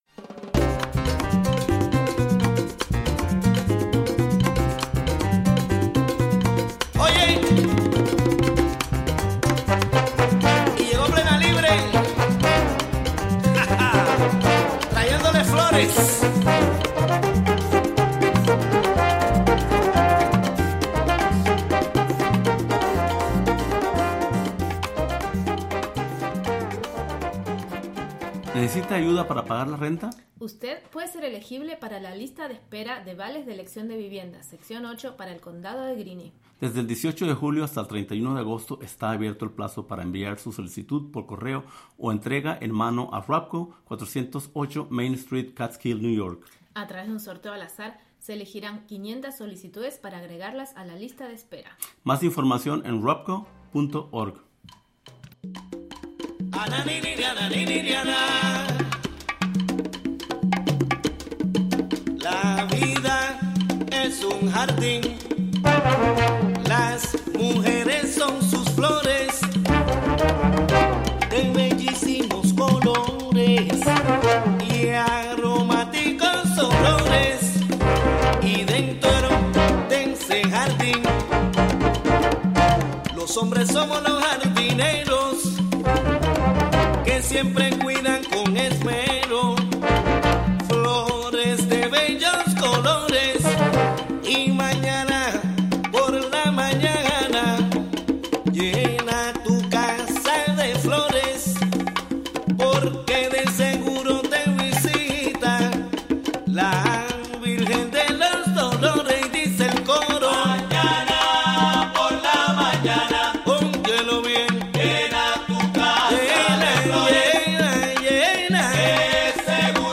11am Un programa imperdible con noticias, entrevistas,...